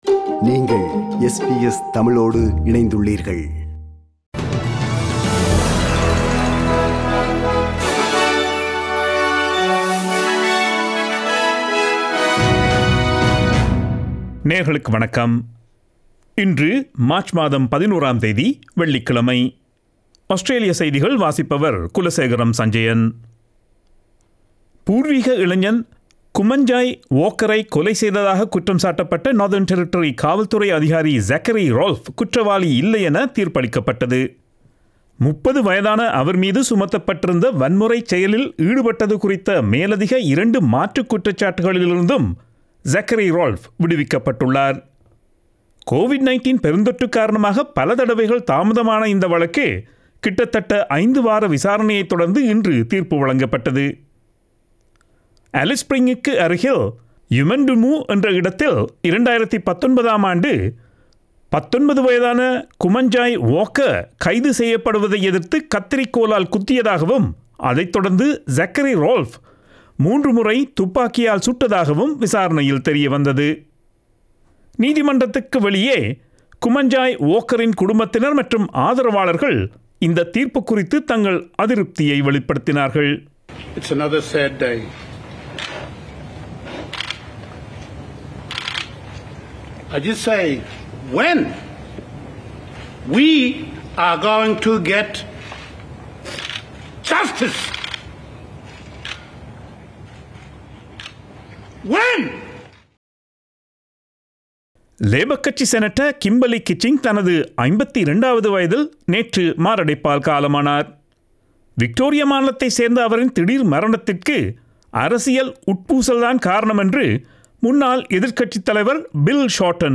Australian news bulletin for Friday 11 March 2022.